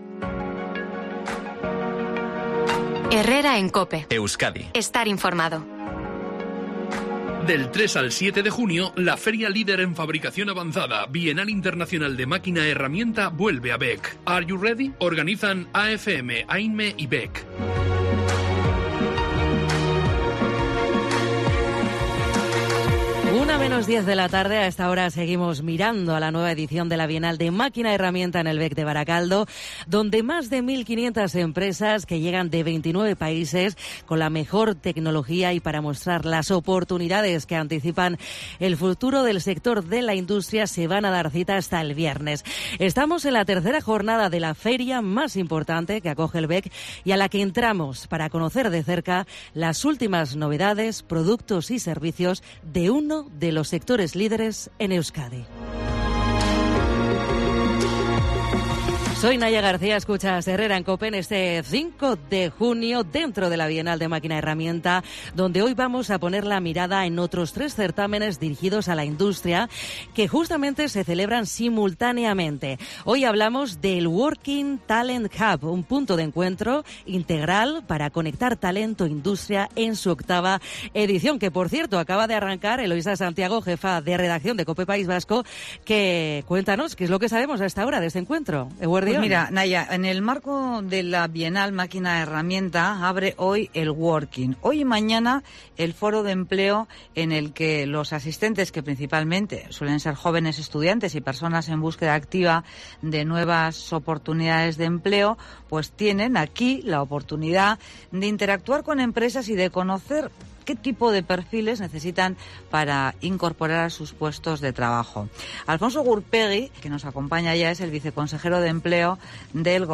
El viceconsejero de Empleo del Gobierno vasco destaca en COPE Euskadi la gran oportunidad laboral que representa el Working Talent Hub en la Bienal de Máquina-Herramienta del BEC